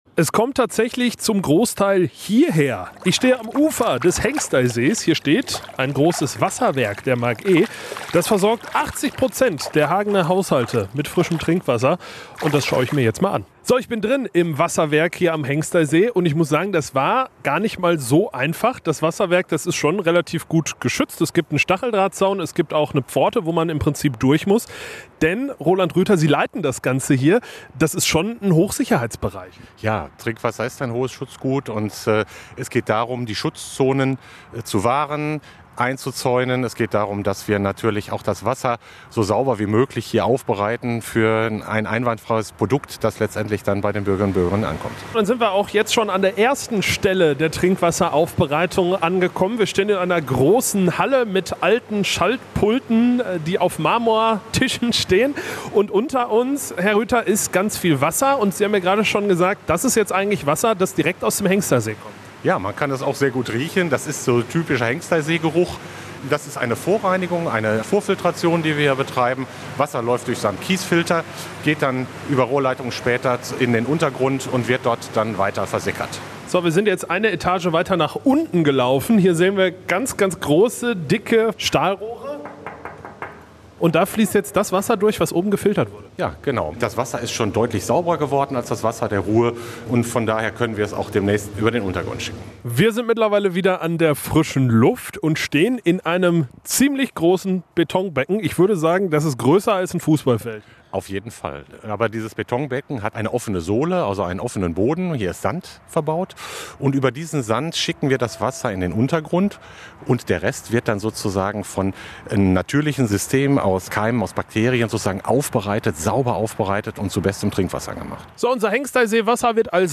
Radiobeitrag zum Nachhören
Dabei sind nicht nur die Fotos in diesem Artikel, sondern auch eine Radioreportage entstanden.
repo-wasserwerk.mp3